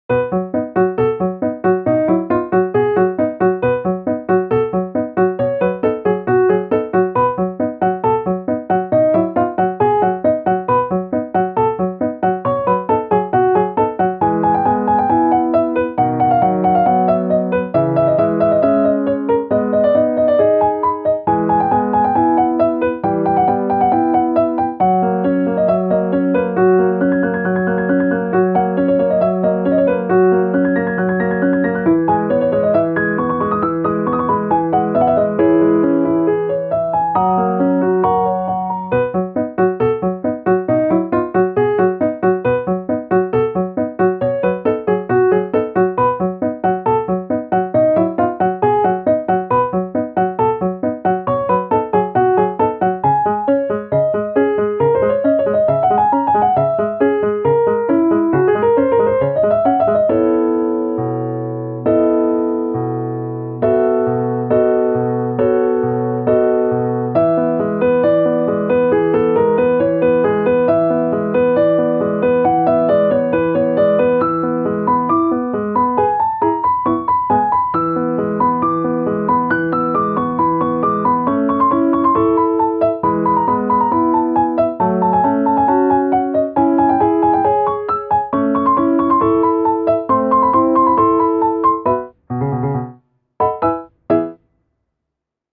ogg(R) - 不思議 リズミカル 明るい